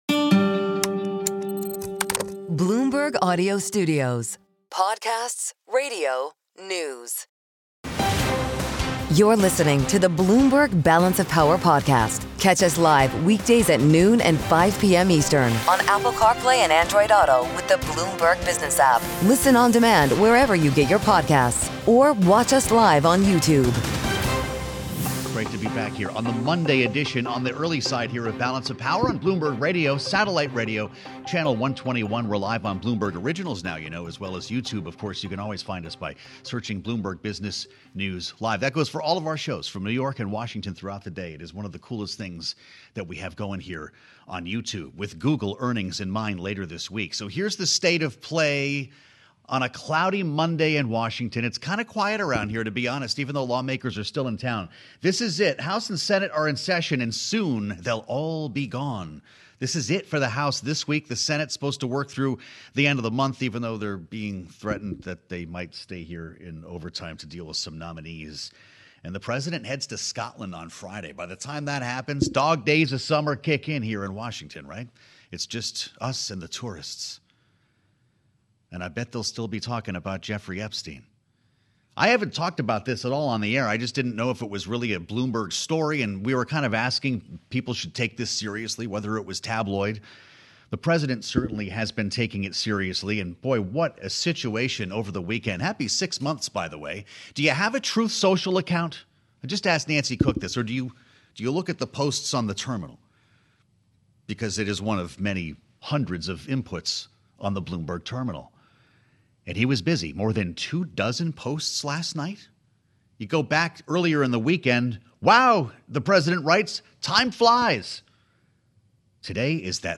including conversations with influential lawmakers and key figures in politics and policy.